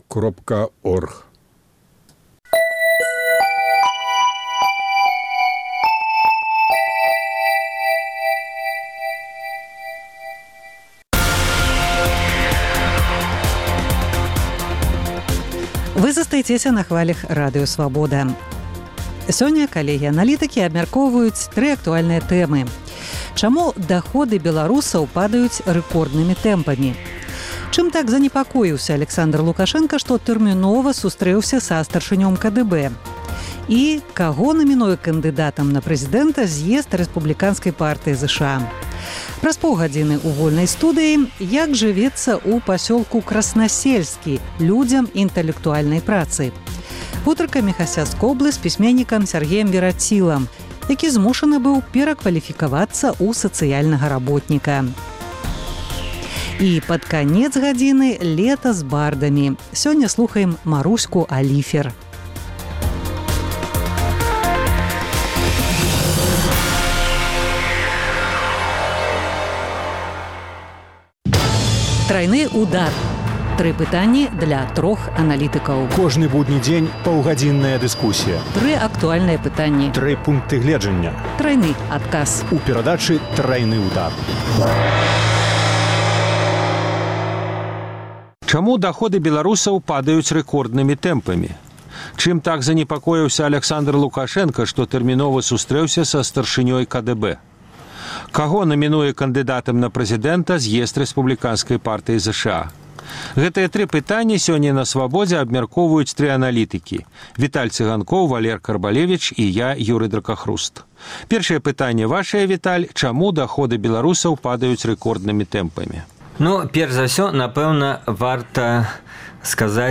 Вэрсіі забойства, этапы яго прафесійнага шляху, голас самога Паўла на Свабодзе, рэпартаж з Кіева і спэцыяльны выпуск "Праскага акцэнту"